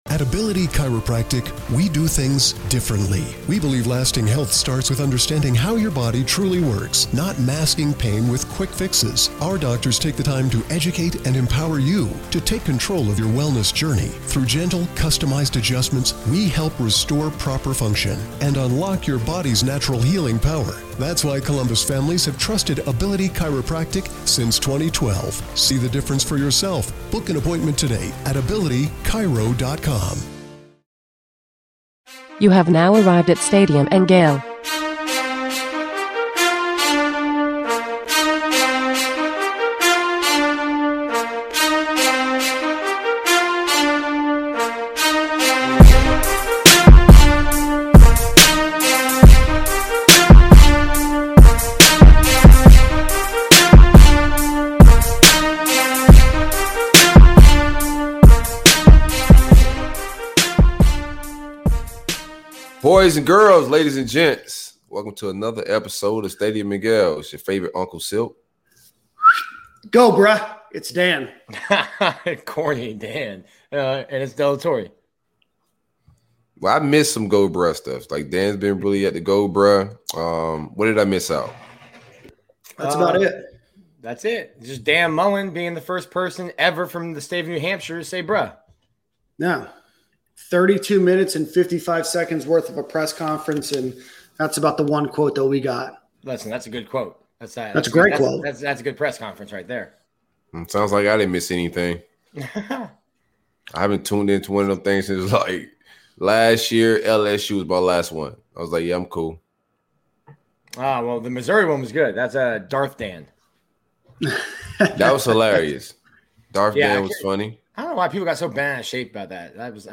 After the first full week of practice, the team scrimmaged Sunday night, and we are here to give you all the details. We also give you a few clips from Coach Mullen's presser on Monday, Gator News of the Week, and close out the show with buy or sell.